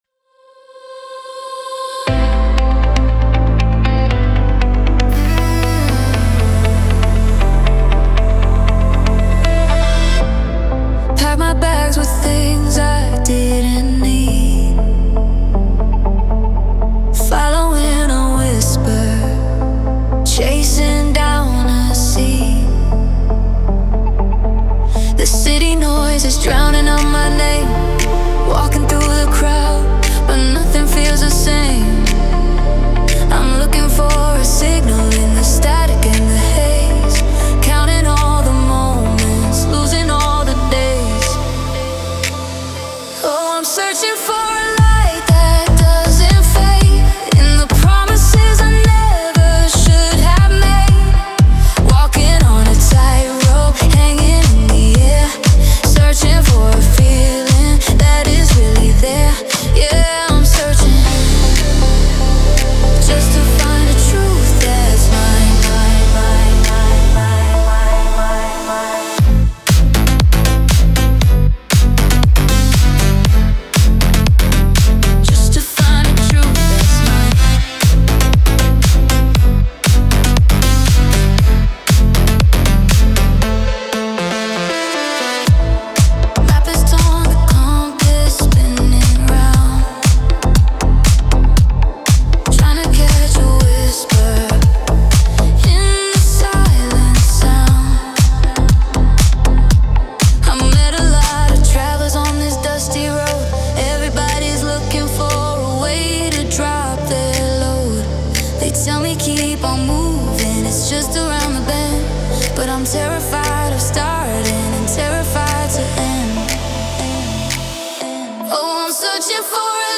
Dance/Pop - 4:14 Min.